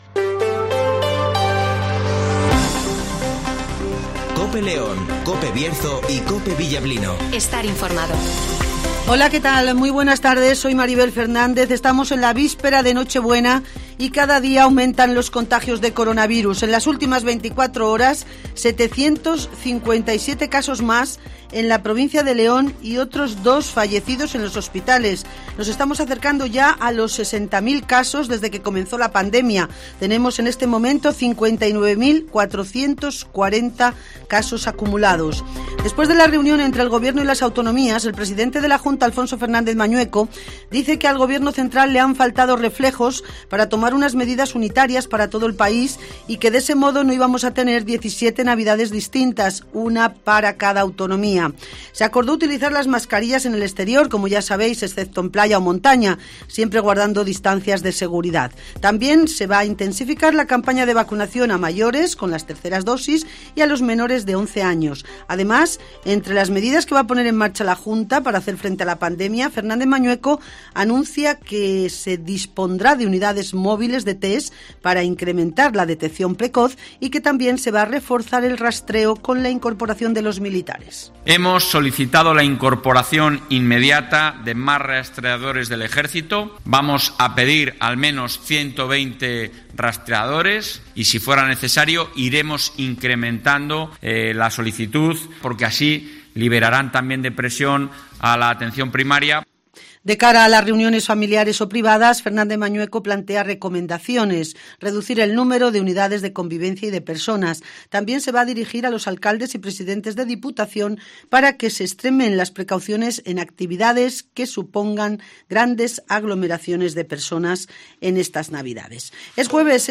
Informativo Mediodía